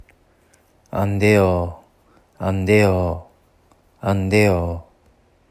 「だめです」と韓国語で言いたい時は안돼요（アンデヨ）と言います。
■「だめです」の発音